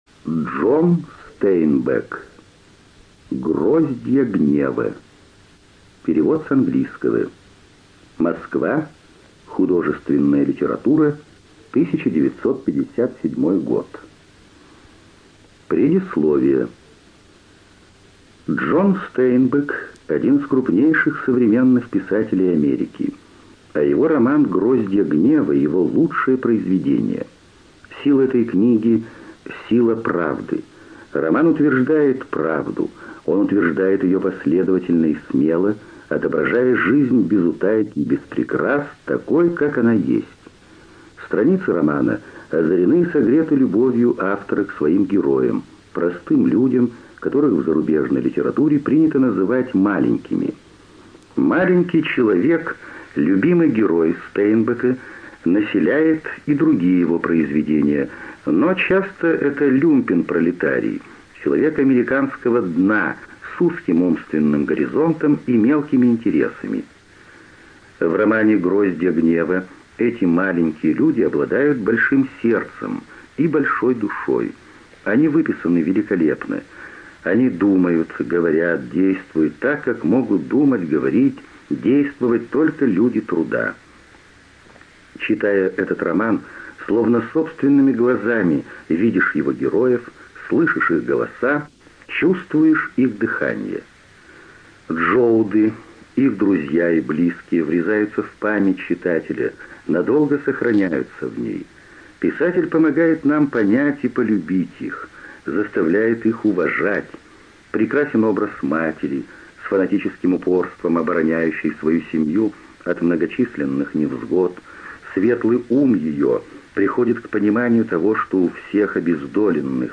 ЖанрКлассическая проза
Студия звукозаписиЛогосвос